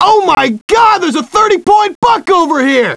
These Deerhunter Wavs Are From A Hunting CD Game Where Deer Hunt People That I Had Years Ago